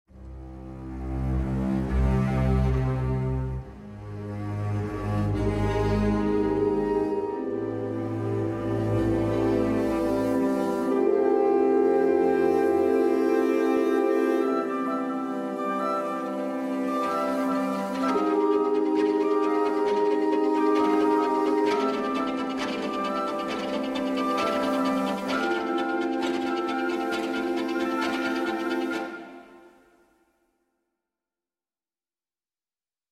Find out how different Sonokinetic instruments produce very different flavours, and still work very well together!